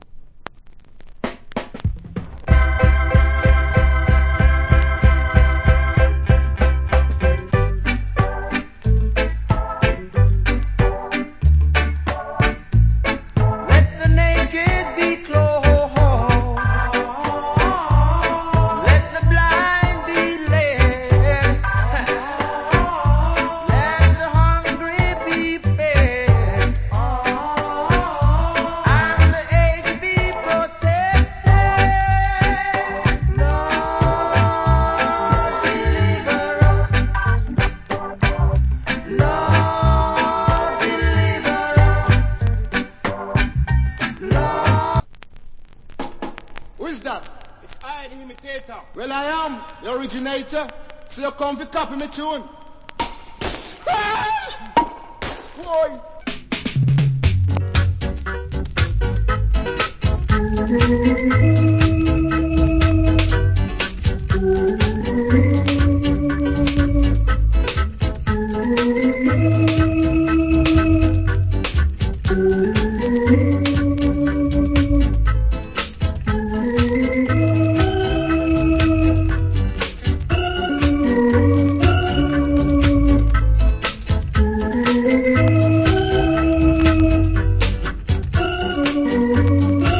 Great Reggae Vocal.